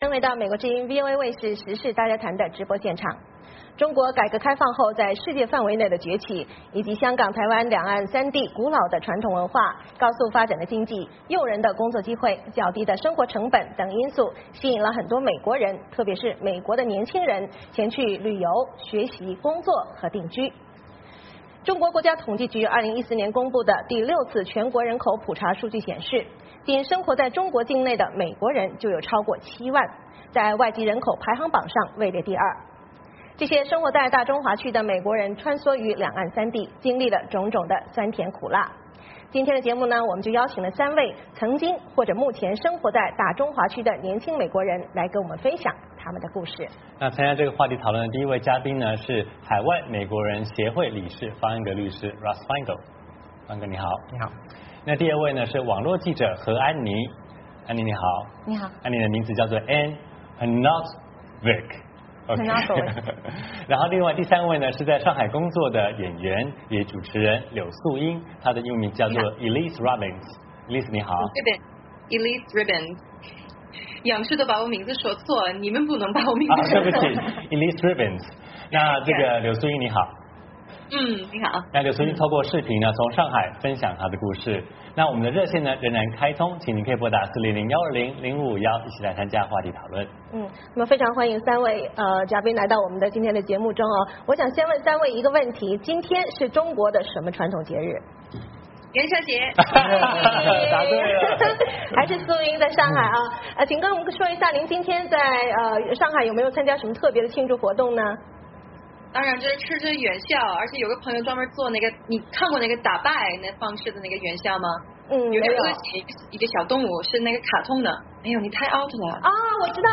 中国改革开放后在世界范围内的崛起，以及香港、台湾两岸三地古老的传统文化、诱人的工作机会、较低的生活成本等因素吸引了很多美国人前去旅游、学习、工作和定居。今天的节目我们就邀请三位曾经或者目前生活在大中华区的年轻美国人来跟我们分享他们的故事。